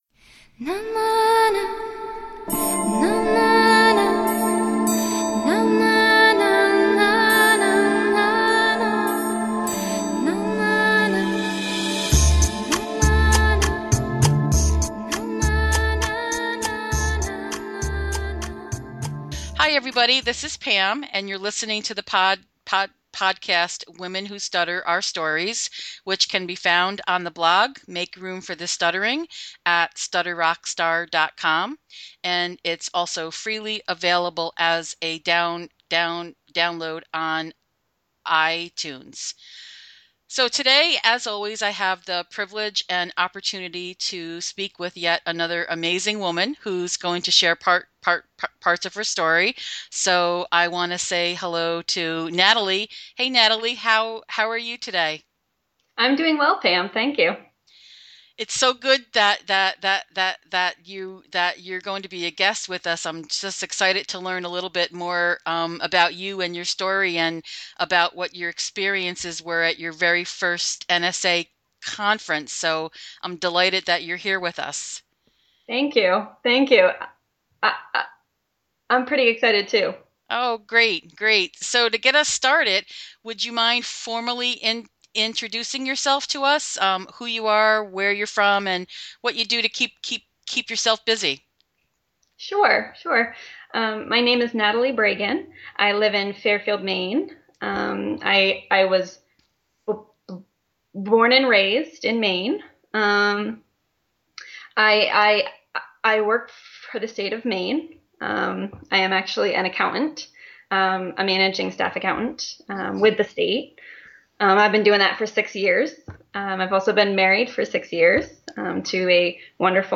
This was a great conversation and a chance to relive conference moments through the eyes of a first time attendee.